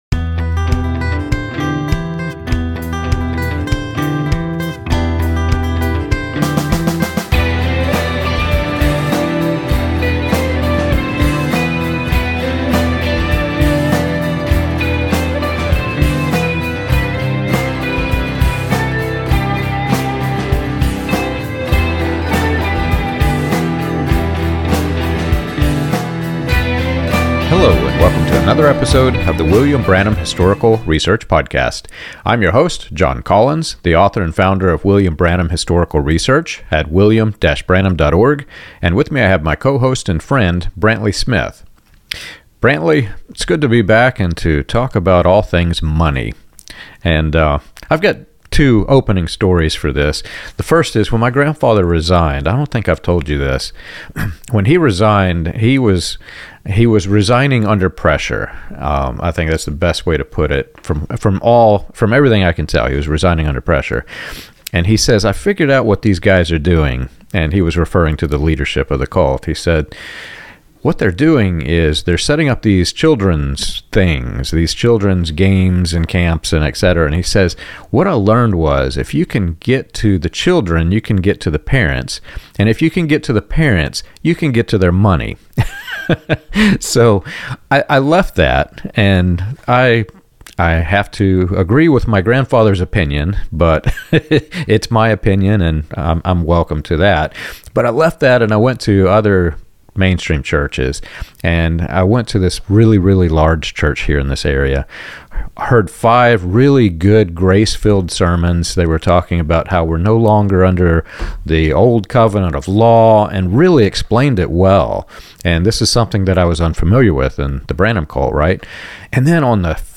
The conversation traces how ministry culture normalized scarcity, guilt, and sacrificial giving, even when young students and workers were struggling to pay rent, buy food, or meet basic needs.